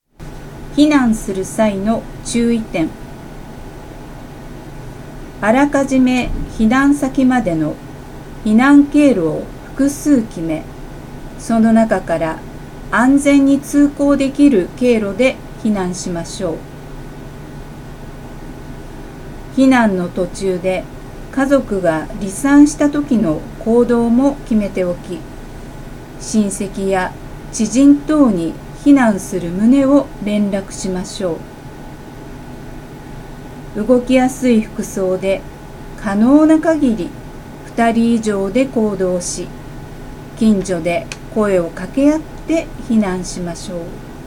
女性消防サポーターによる「音声版ハザードマップ」
女性消防サポーターが、目が不自由な方に向けての防災に関する知識や災害リスク情報を読み上げ、音声で発信します。